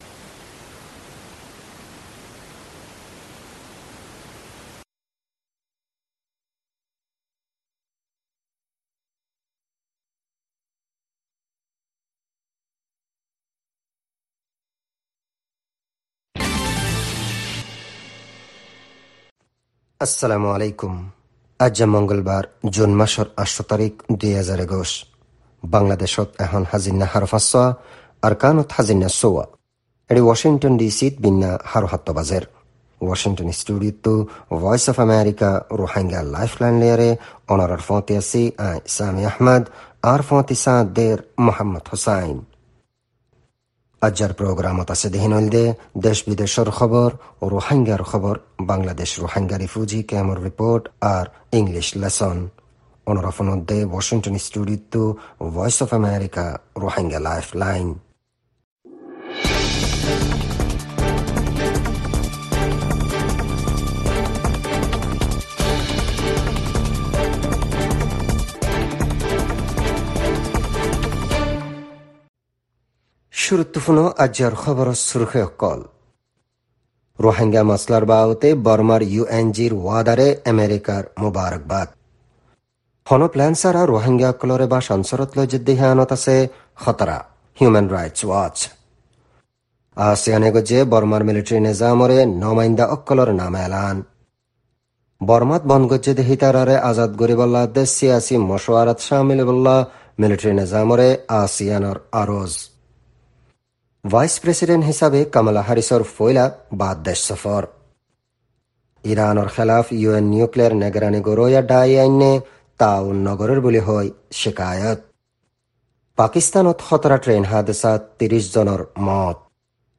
Rohingya “Lifeline” radio